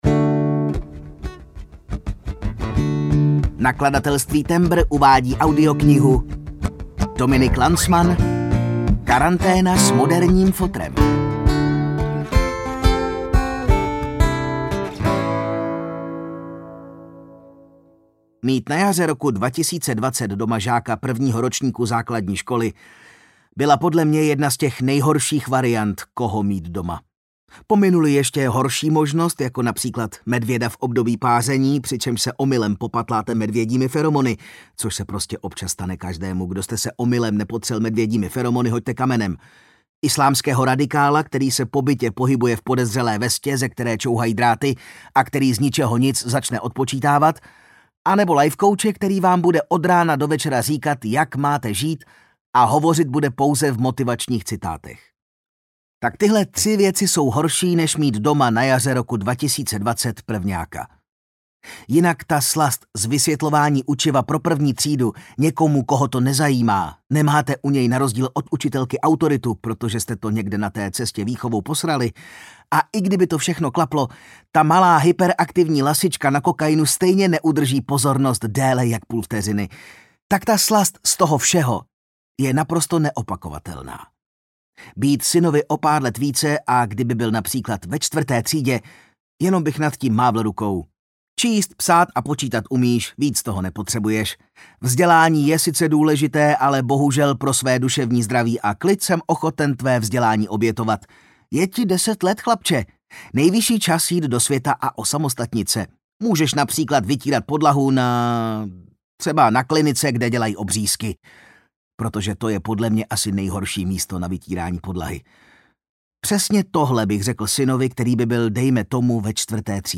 Karanténa s moderním fotrem audiokniha
Ukázka z knihy